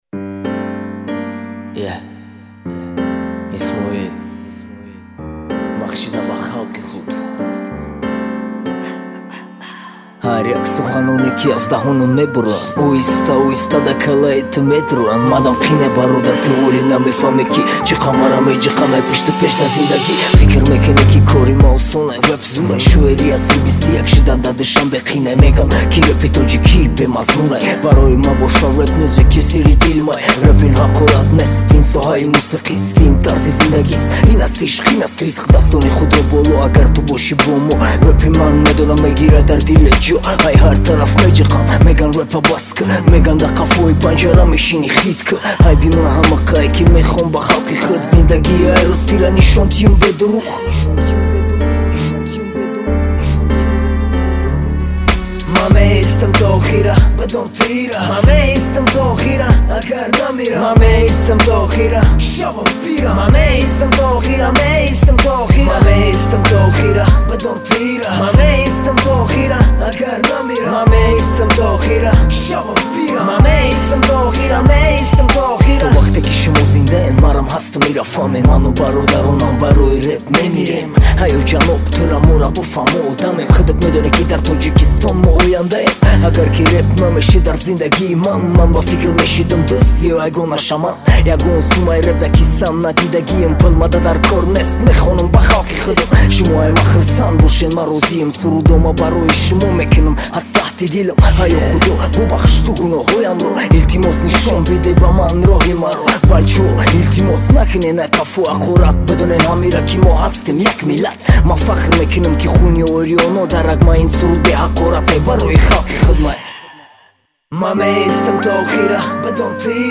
Категория: Тадж. Rap